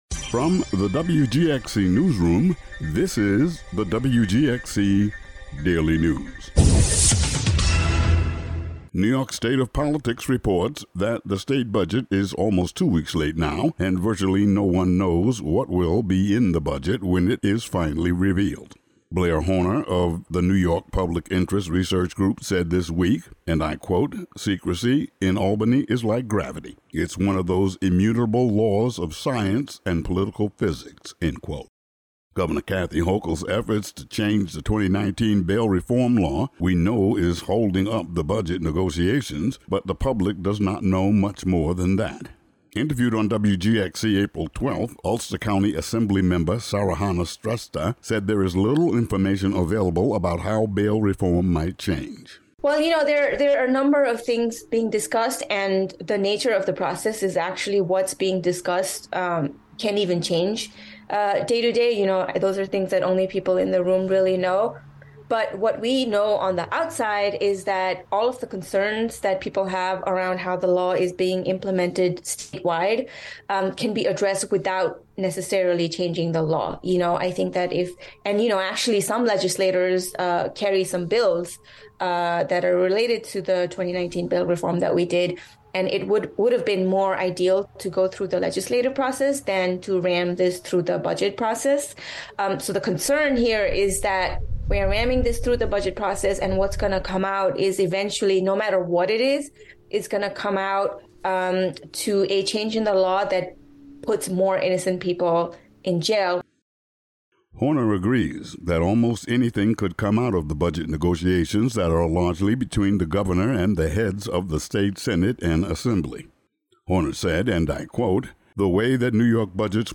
Today's daily local audio news.